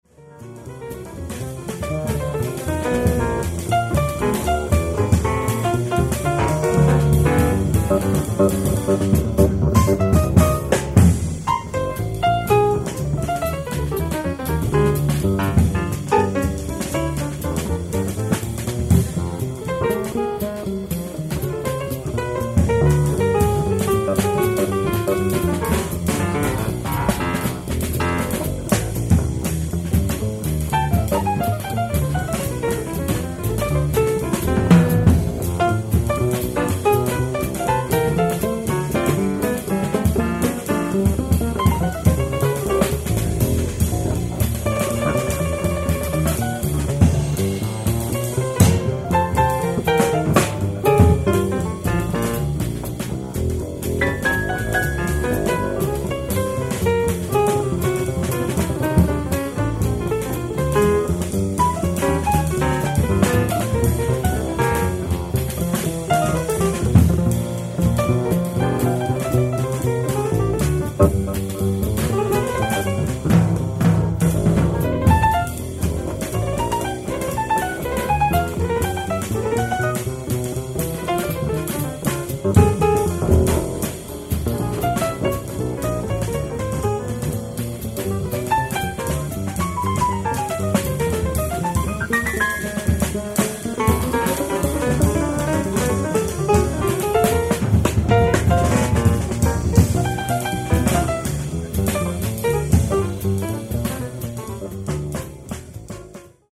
ライブ・アット・ヴィラ・インペリアーレ、ジェノヴァ、イタリア 07/25/1983
※試聴用に実際より音質を落としています。